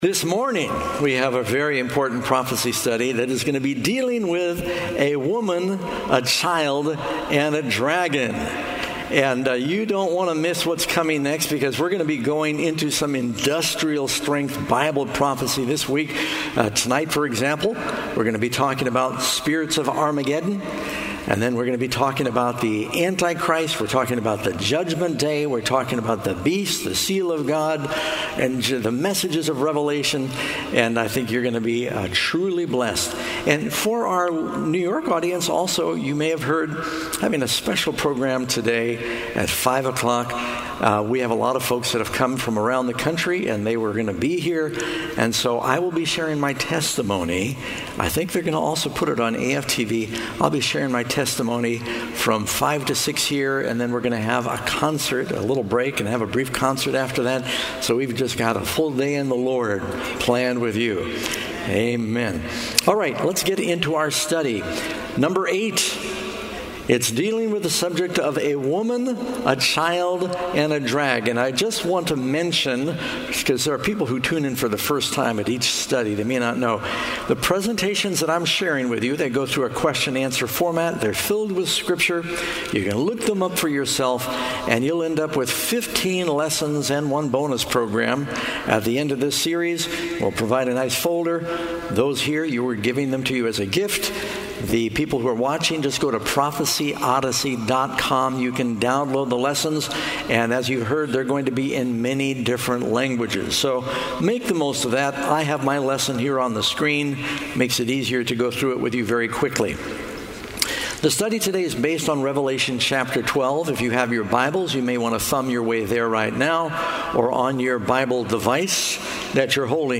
Pastor Doug Batchelor on 2024-09-28 - Sermons and Talks 2024